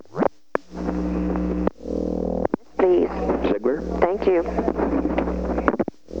Conversation: 018-053
Recording Device: White House Telephone
The White House Telephone taping system captured this recording, which is known as Conversation 018-053 of the White House Tapes.
The President talked with the White House operator.